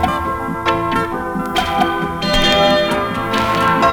AMBIENLOOP-R.wav